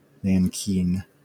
Ääntäminen
Vaihtoehtoiset kirjoitusmuodot nankin Ääntäminen US Haettu sana löytyi näillä lähdekielillä: englanti Kieli Käännökset italia nanchino Määritelmät Substantiivi A type of cotton cloth originally from Nanking in China.